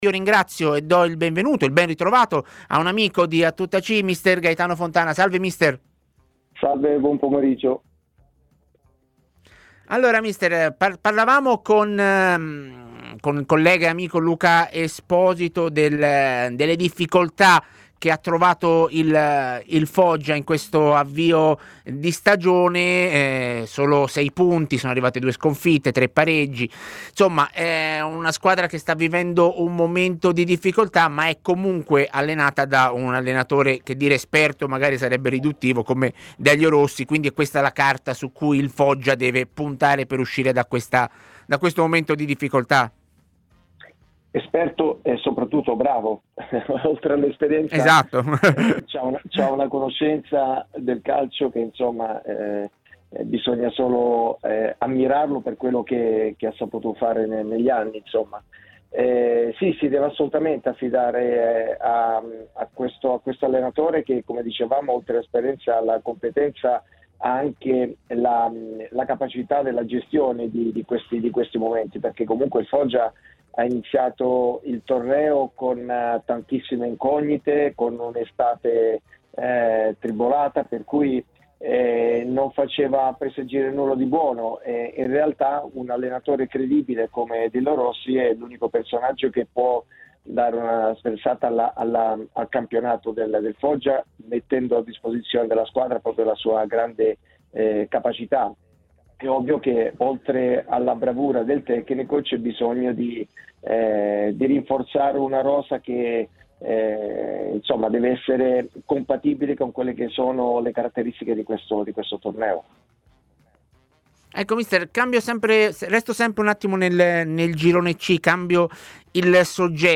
trasmissione in onda su TMW Radio e su Il 61, canale 61 del digitale terrestre. Il Foggia sta vivendo un momento di difficoltà in questo avvio di stagione.